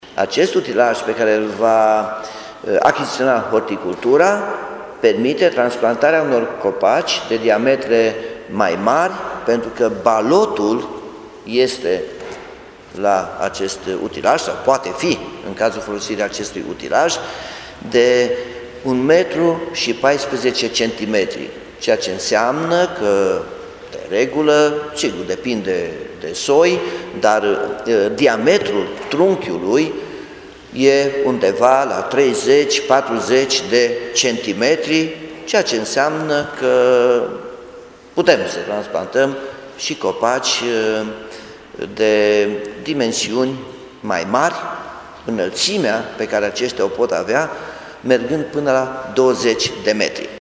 Potrivit primarului Nicolae Robu, utilajul pe care îl va cumpăra Horticultura permite mutarea copacilor cu diametru de până la jumătate de metru: